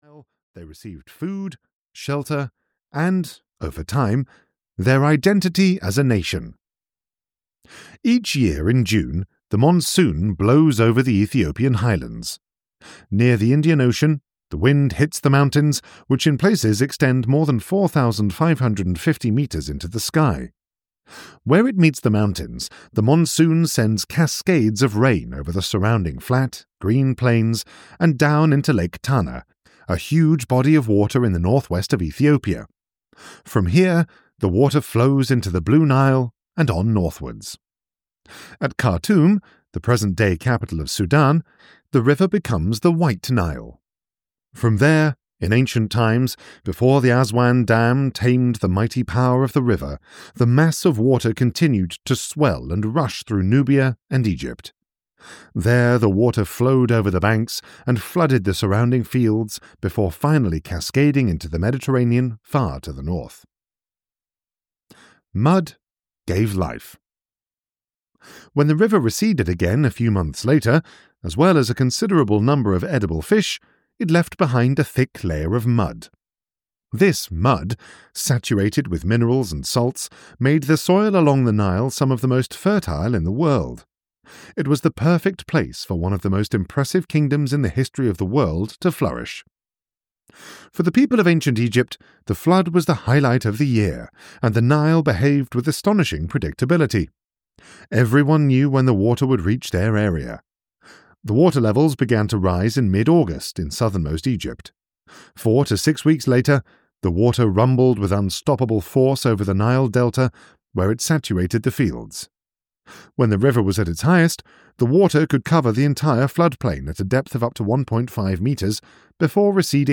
Audio knihaSecrets of the Ancient Egyptians (EN)
Ukázka z knihy